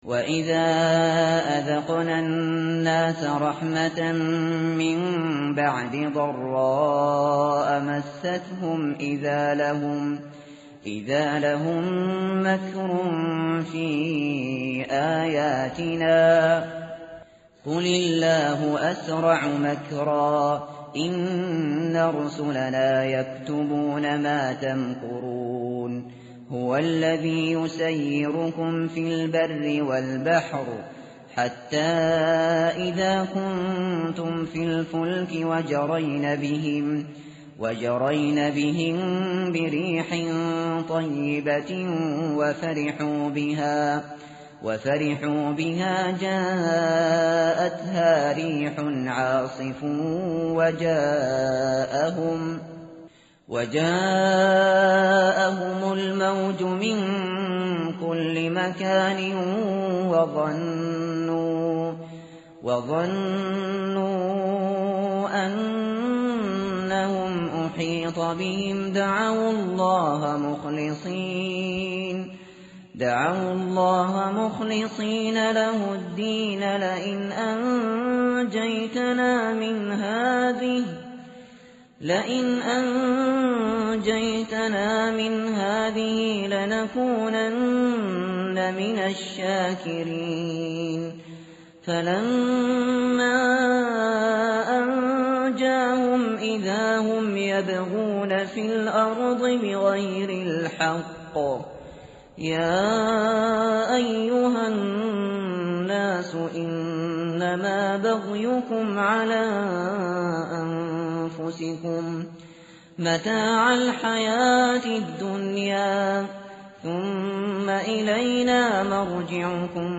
tartil_shateri_page_211.mp3